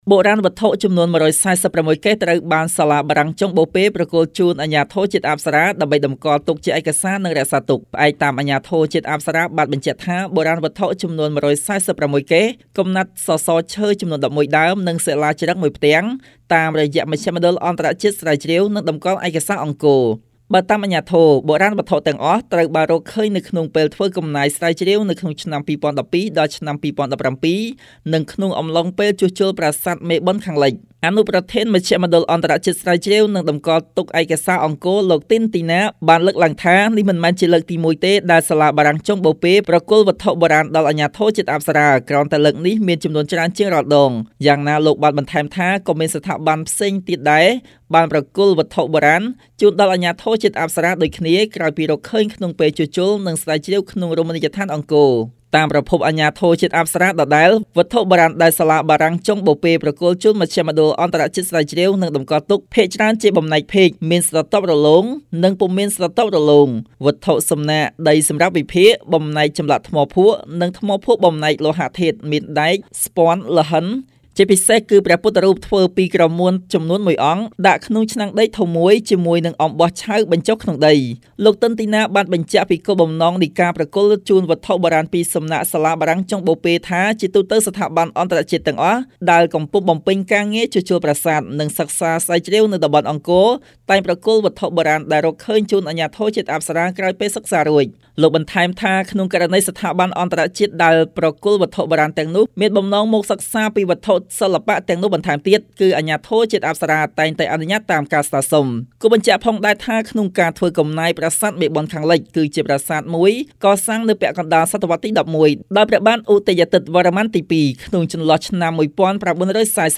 ជូននូវសេចក្ដីរាយការណ៍៖